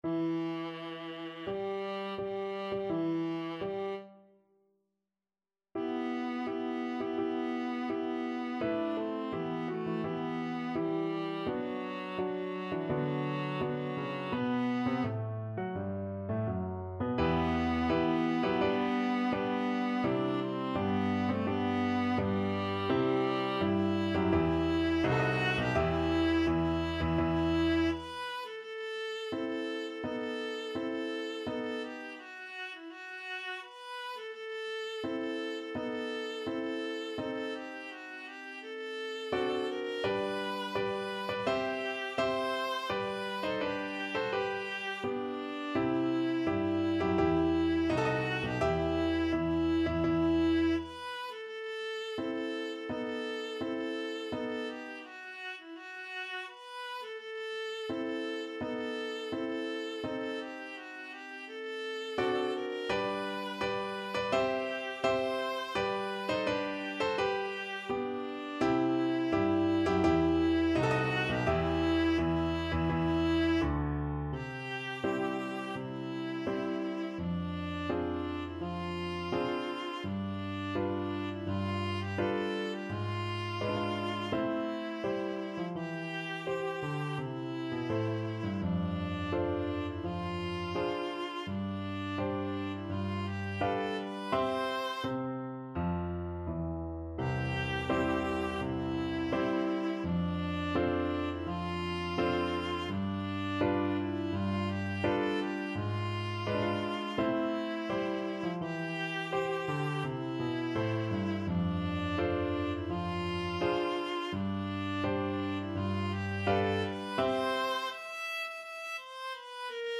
Classical Chopin, Frédéric Marche funebre, Op.72 No.2 (Trauermarsch) Viola version
E minor (Sounding Pitch) (View more E minor Music for Viola )
Tempo di Marcia =84
4/4 (View more 4/4 Music)
Viola  (View more Intermediate Viola Music)
Classical (View more Classical Viola Music)